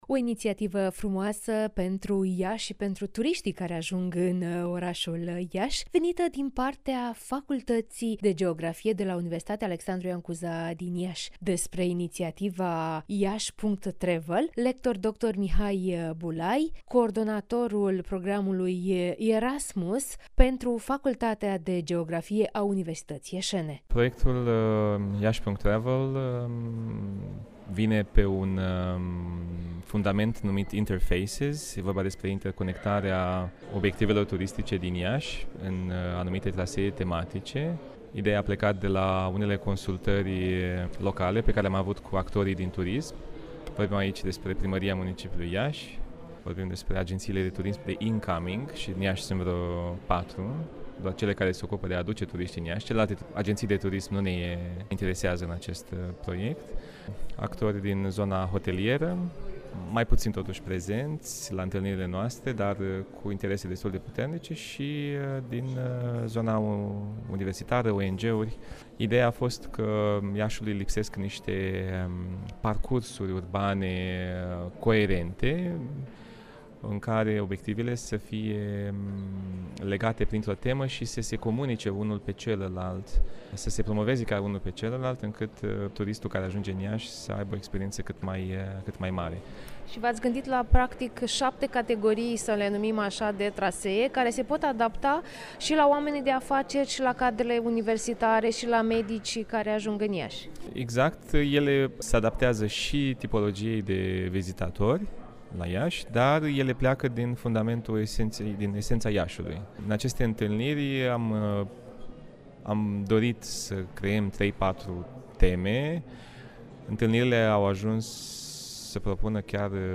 (INTERVIU) Trasee turistice prezentate de membri ai Facultății de Geografie și Geologie